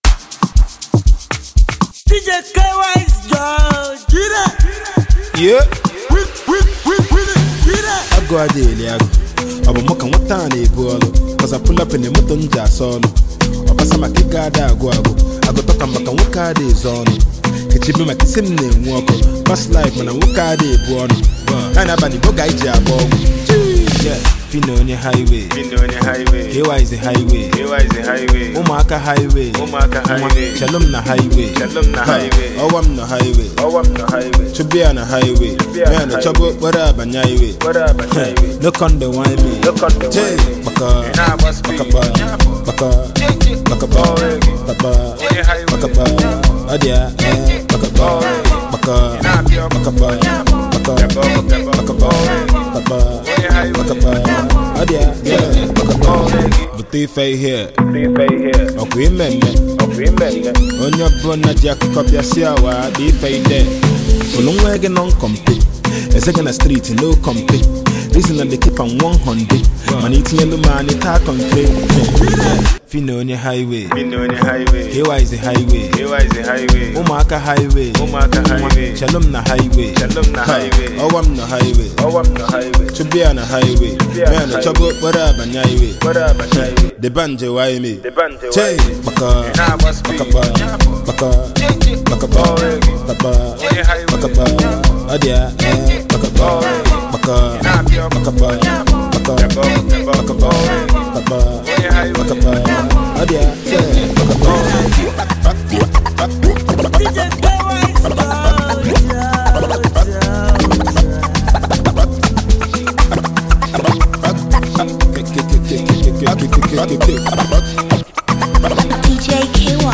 Amapiano influenced record
Indigenous rapper